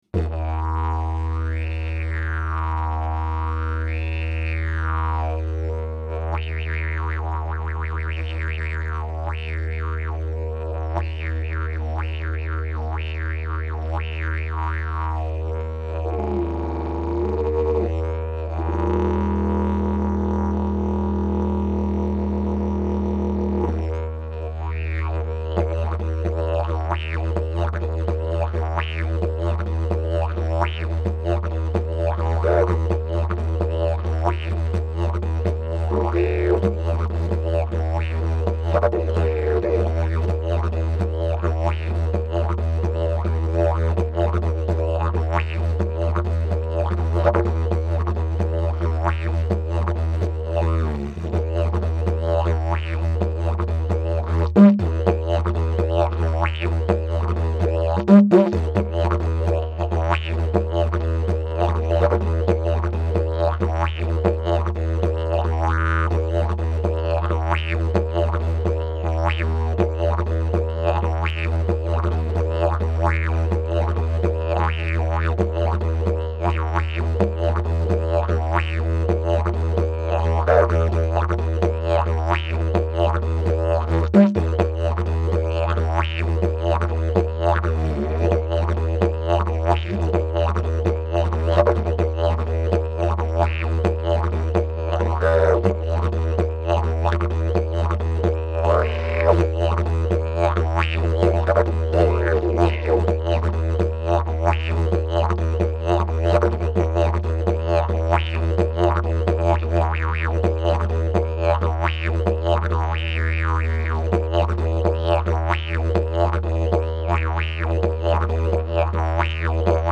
j'ai un micro T-bone CD55, (à la base fait pour des toms), ça coûte vraiment pas cher (35 neuros chez thomann si j'me souviens bien), et je trouve que ça convient vraiment bien pour le didge.........ça laisse passer les harmoniques, ça encaisse bien les cris.........
si tu veux un aperçu du son, voici une prise récente que j'ai faite avec ce micro sans égalisation, sans retoucher le son: